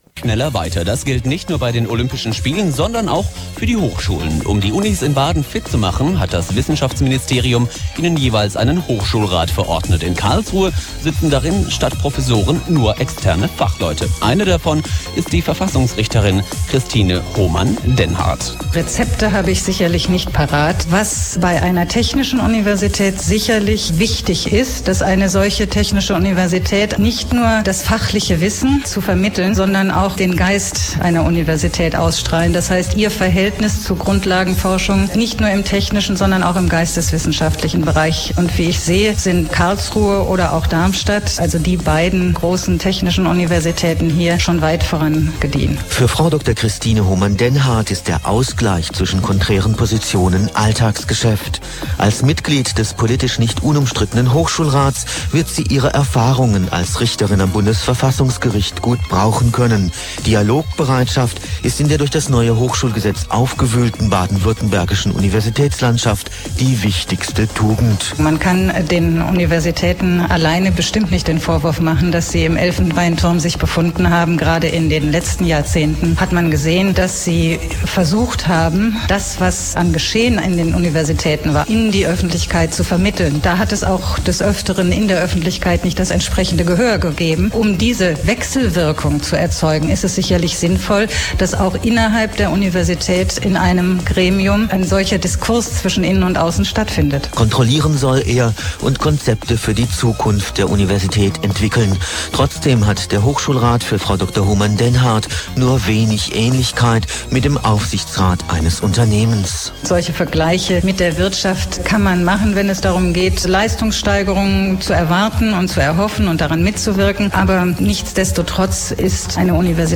Interviewter
Christine Hohmann-Dennhardt